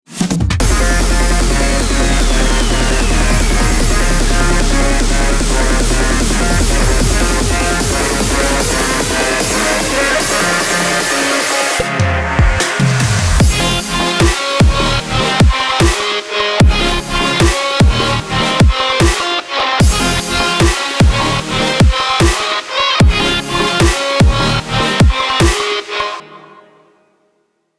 ישבתי על סרום וניסתי ליצור סאונד של גיטרה חשמלית זה נראה לי יצא יפה אבל מלוכלך (וזה יצא יותר מונו מאשר סטריאו נראה לי) אשמח לתגובה על זה מהמומחים האם זה באמת נשמע גיטרה חשמלית?
ונראה לי שאפשר לעשות על זה אתגר שתקחו את הקטע הזה ותתנו לו את הליווי באיזה זאנר שאתם רוצים (טמפו 150 אבל אפשר לשנות)